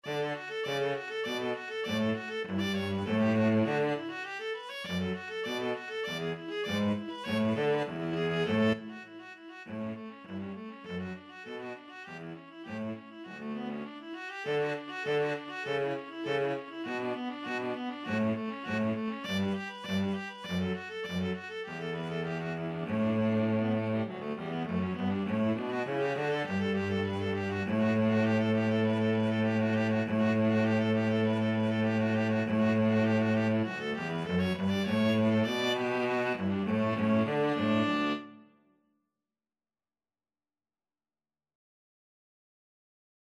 Free Sheet music for Viola-Cello Duet
D major (Sounding Pitch) (View more D major Music for Viola-Cello Duet )
4/4 (View more 4/4 Music)
Allegro =200 (View more music marked Allegro)
Classical (View more Classical Viola-Cello Duet Music)